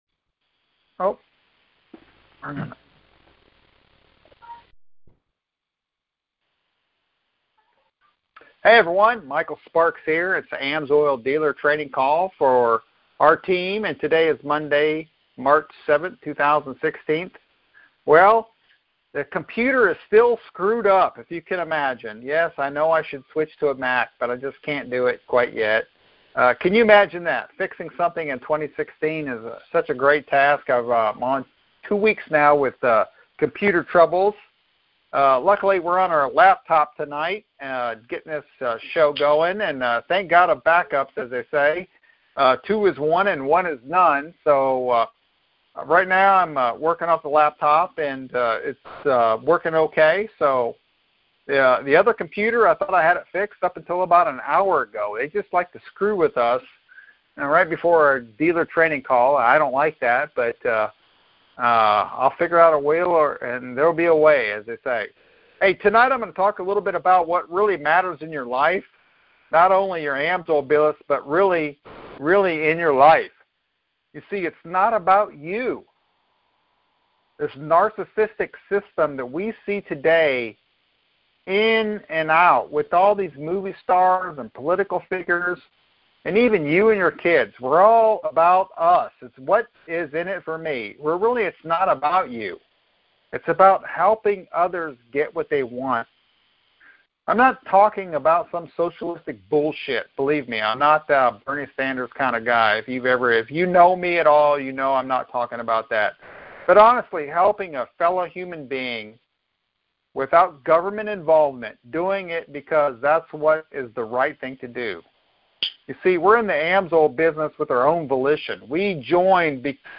Sparks Team AMSOIL Dealer Training Call |March 7th, 2016